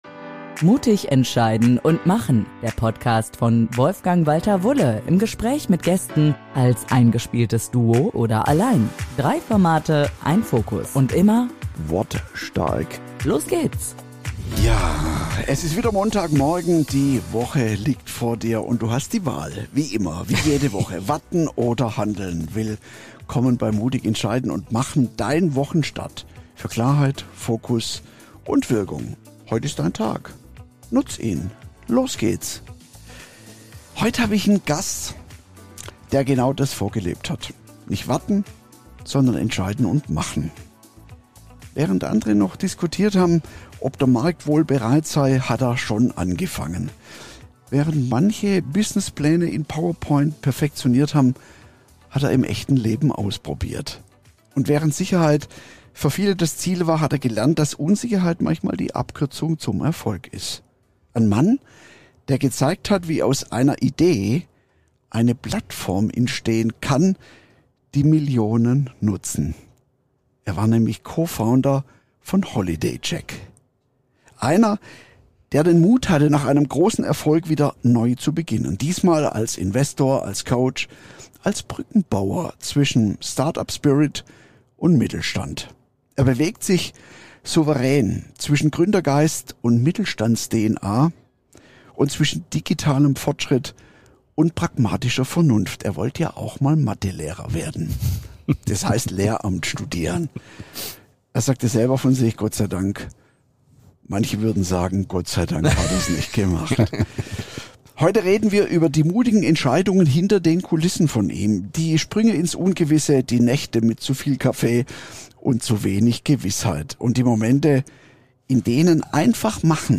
Ein Gespräch über Unternehmergeist, innere Freiheit und die Kunst, einfach anzufangen – statt ewig zu planen.